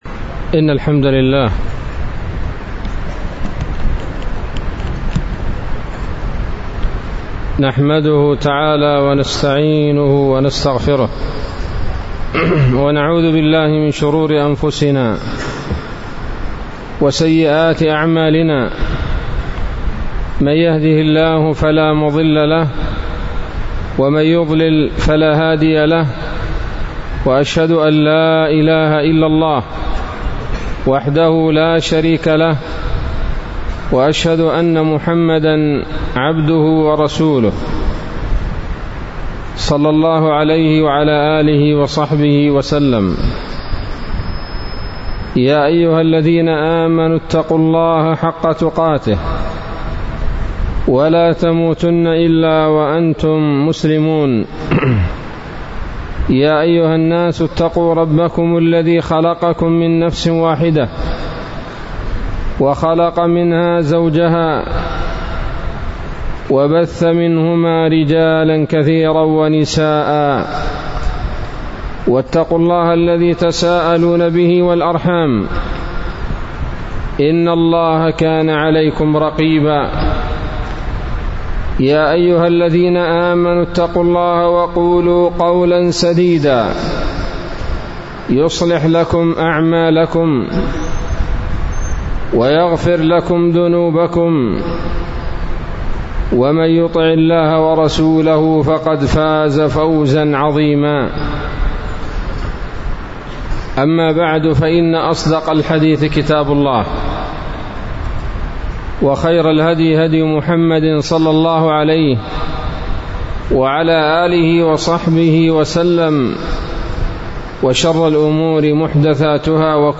محاضرة قيمة بعنوان: (( مكر الأعداء بأهل اليمن )) ليلة السبت 09 ذو القعدة 1442هـ، بمدينة لودر